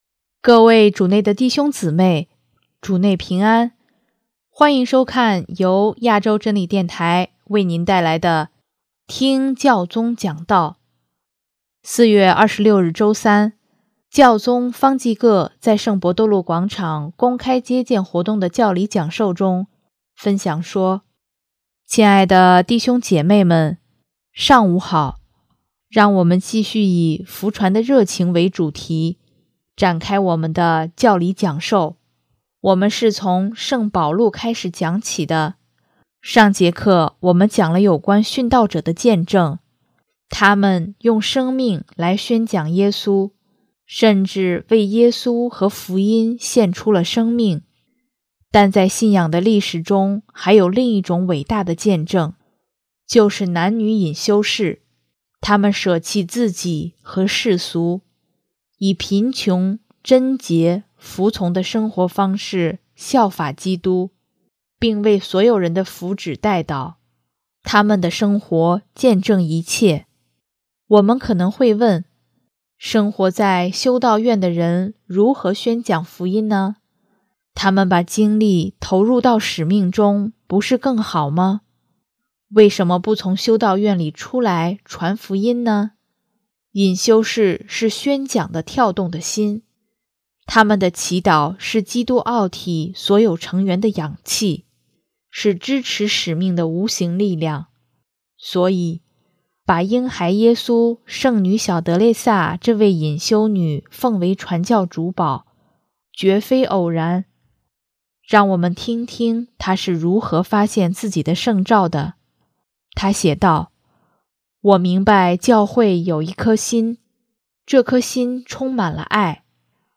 4月26日周三，教宗方济各在圣伯多禄广场公开接见活动的教理讲授中，分享说：